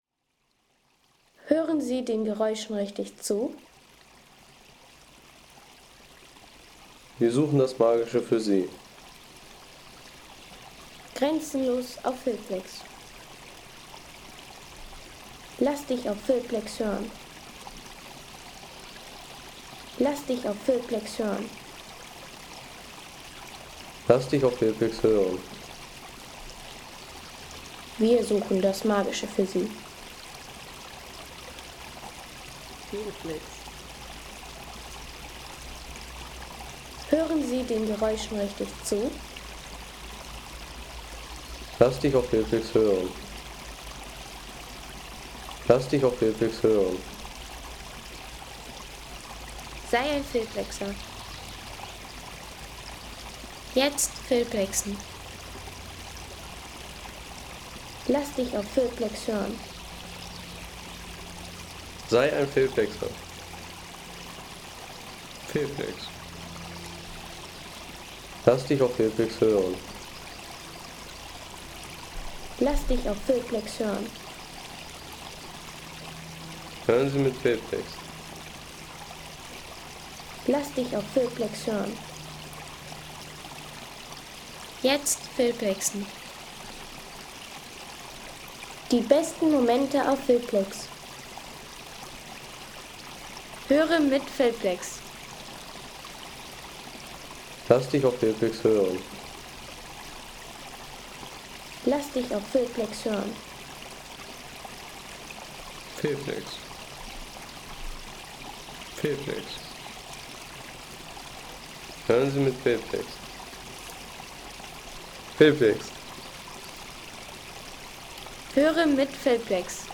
Wasserbach im Buchenwald der Rhönlandschaft
Landschaft - Bäche/Seen